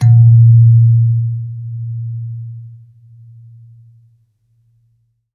kalimba_bass-A#1-mf.wav